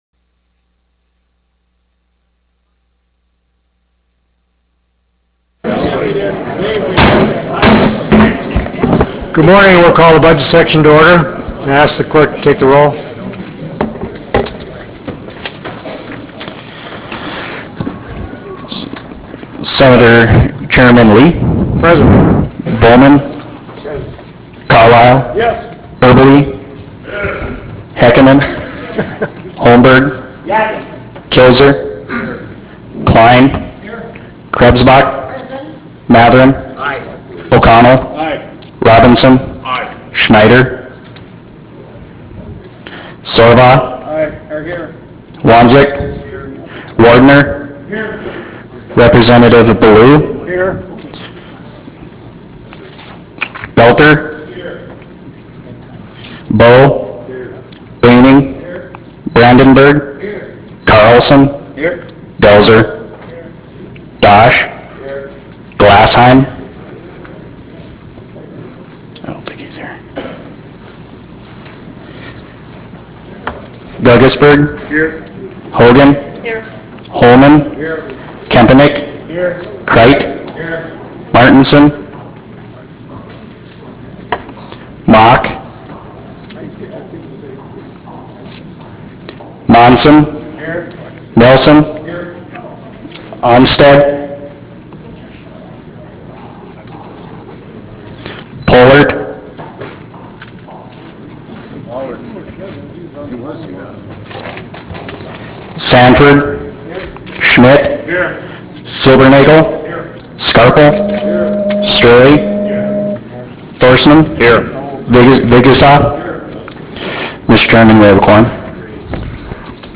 Brynhild Haugland Room State Capitol Bismarck, ND United States